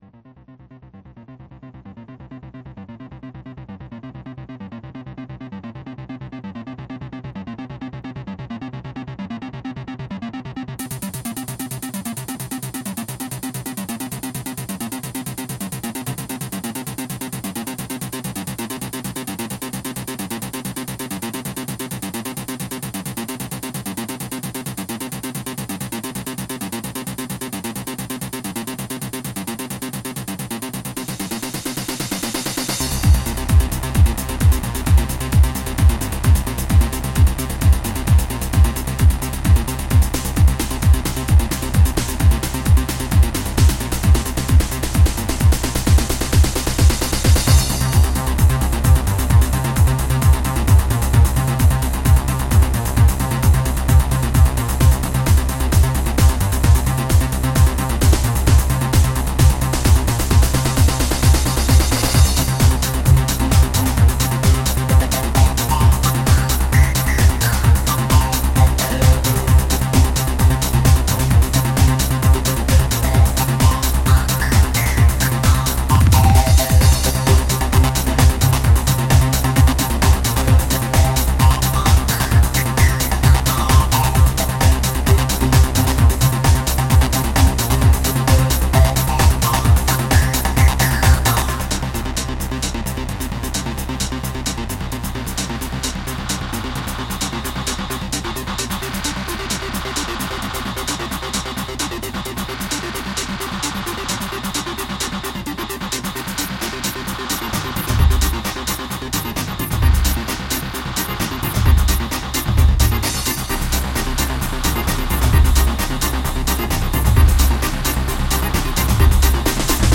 EBM